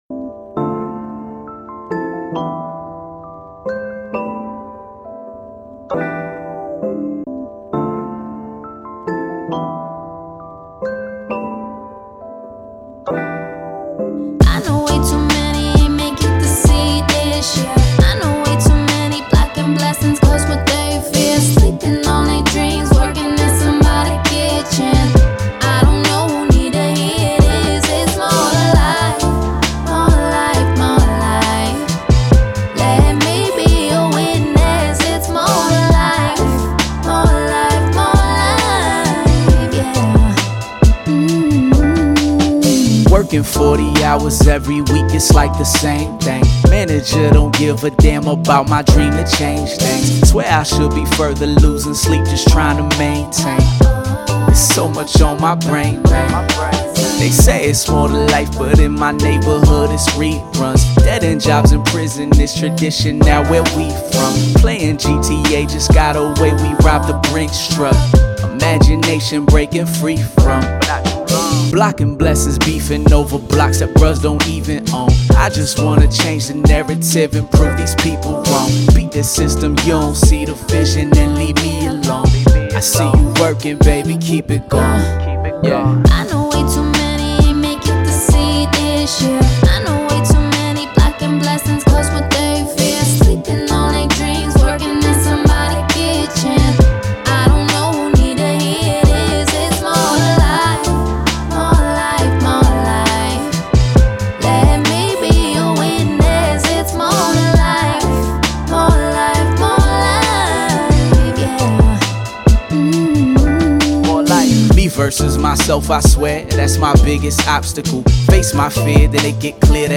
Hip Hop
A Minor